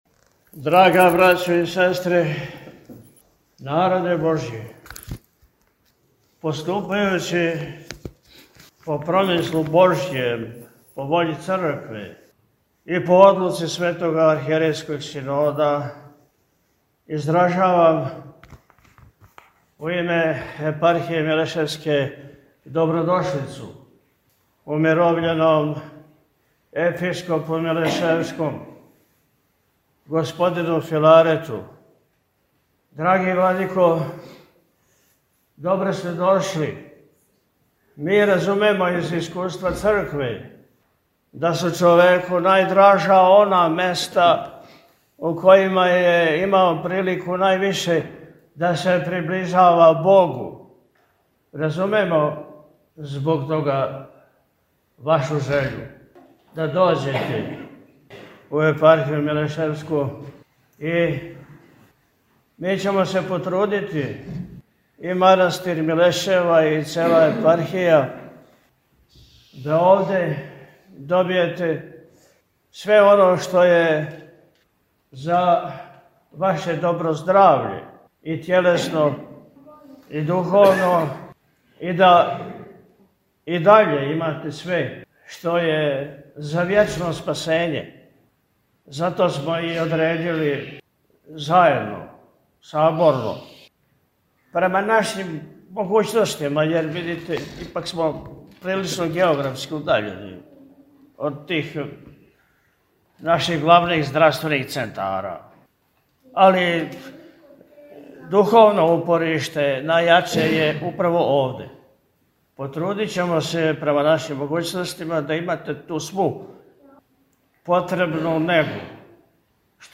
Његово Високопреосвештенство Архиепископ и Митрополит милешевски г. Атанасије дочекао је у четвртак 27. марта 2025. године, у манастиру Милешеви, Његово Преосвештенство умировљеног Епископа милешевског г. Филарета, коме је према одлуци Светог Архијерејског Синода Српске Православне Цркве ова светиња одређена као место пребивалишта.
Pozdravne-reci-Mitropolita-Atanasija.mp3